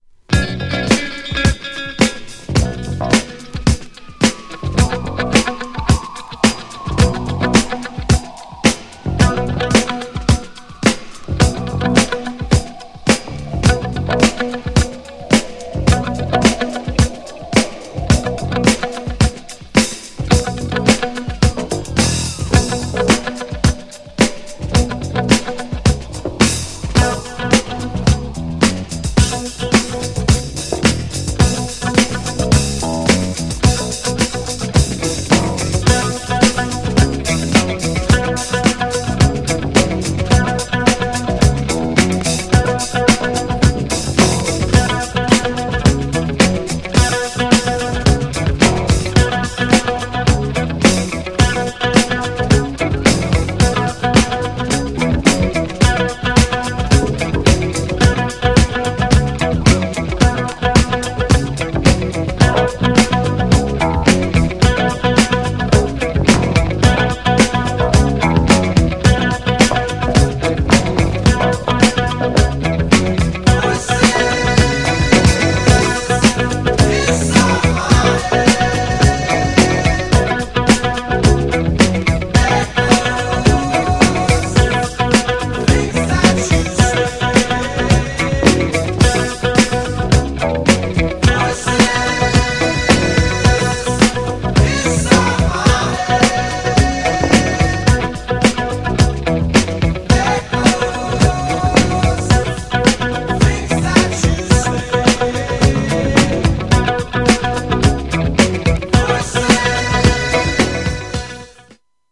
GENRE Dance Classic
BPM 151〜155BPM